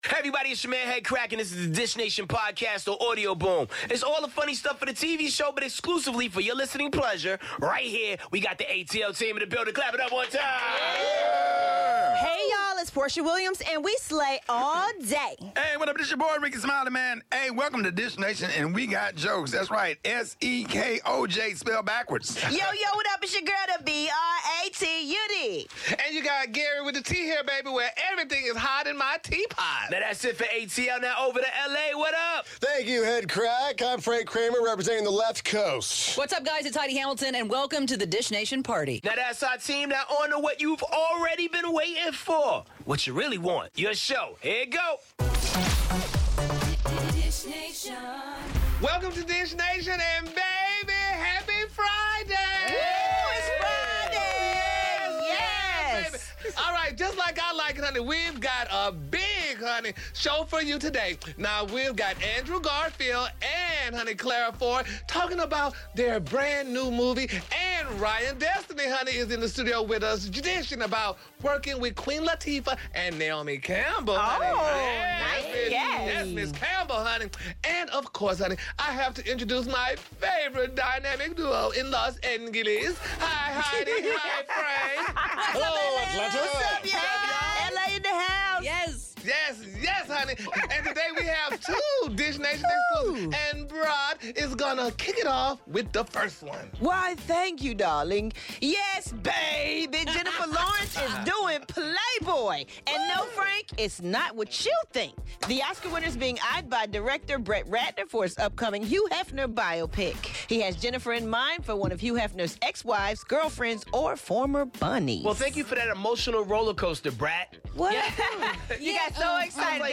We dish with Andrew Garfield and Claire Foy about their new movie 'Breathe' and chat with Ryan Destiny from Fox's 'Star' plus all the latest on Kanye West, Jennifer Lawrence, Pink, Da Brat and more.